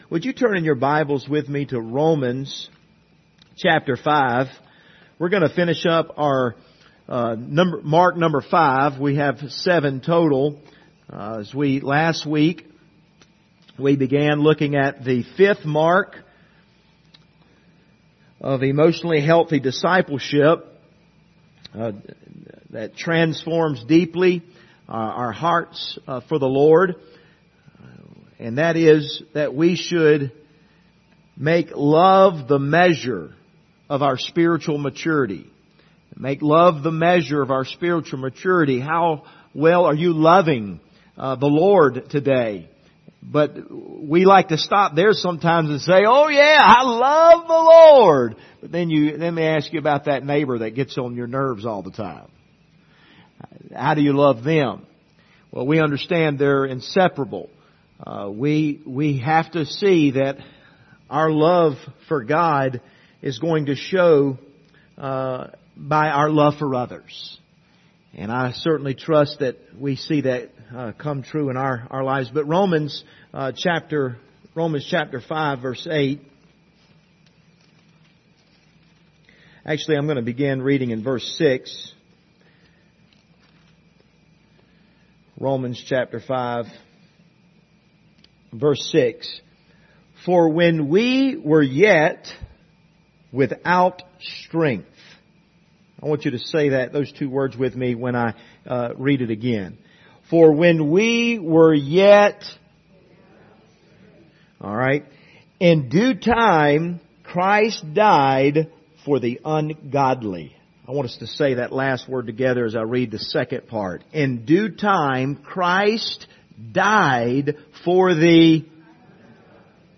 Passage: Romans 5 Service Type: Wednesday Evening